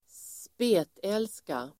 Ladda ner uttalet
Uttal: [²sp'e:tel:ska]